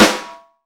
• Piercing Snare Drum Sound D# Key 21.wav
Royality free snare drum tuned to the D# note. Loudest frequency: 2089Hz
piercing-snare-drum-sound-d-sharp-key-21-XDo.wav